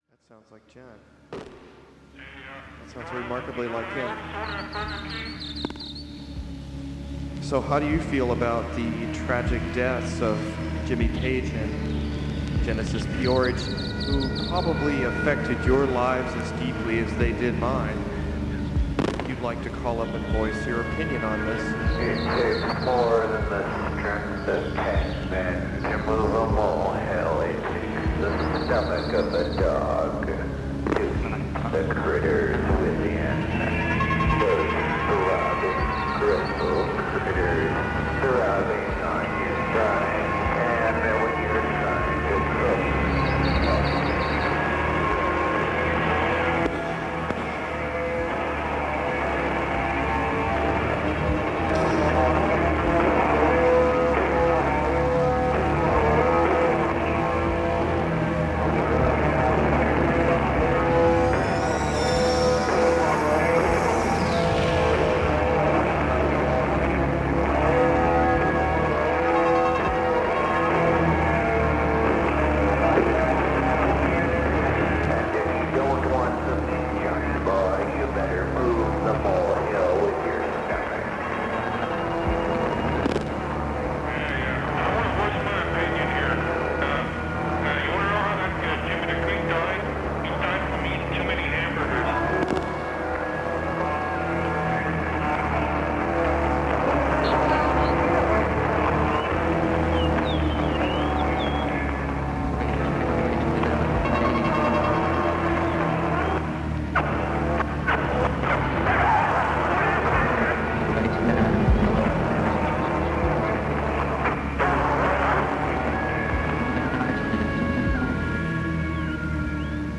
I've snipped a 8-minute snippet of 1:45 > 1:53 that has the siren like sound that I'm trying to identify at :20, :35, and 1:10 (of this 8-minute snippet).